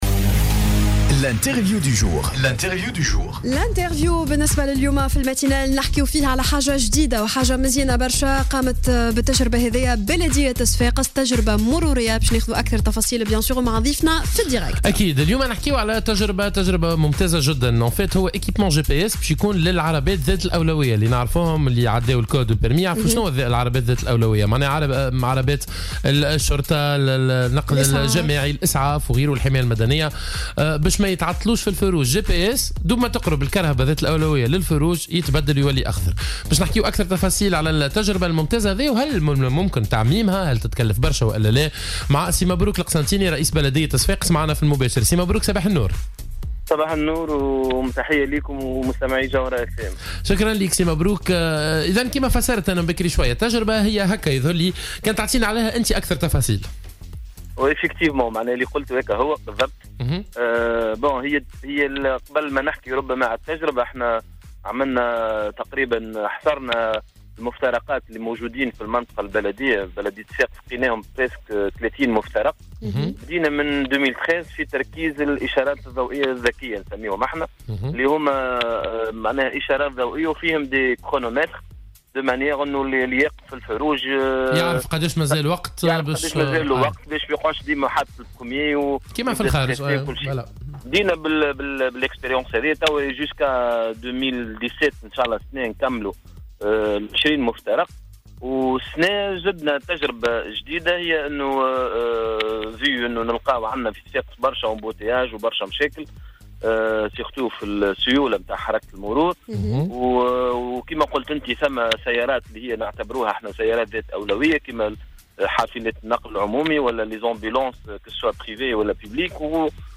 قال مبروك القسنطيني رئيس بلدية صفاقس خلال مداخلته في برنامج "صباح الورد" على موجات الجوهرة اف ام، إن البلدية انطلقت أمس في تطبيق تجربة جديدة تتمثل في تركيز إشارات ضوئية ذكية على مستوى 3 مفترقات، لتسهيل مرور العربات ذات الأولوية.